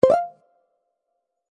notification.mp3